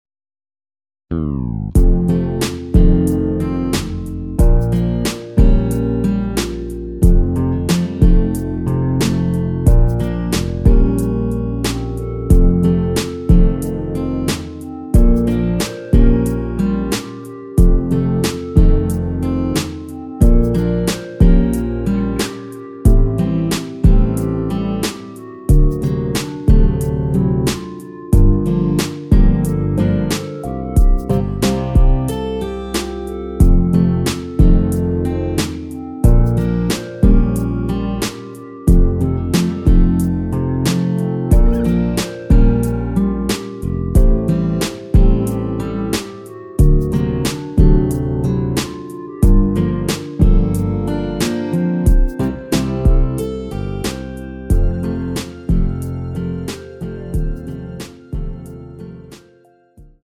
노래방에서 노래를 부르실때 노래 부분에 가이드 멜로디가 따라 나와서
앞부분30초, 뒷부분30초씩 편집해서 올려 드리고 있습니다.
중간에 음이 끈어지고 다시 나오는 이유는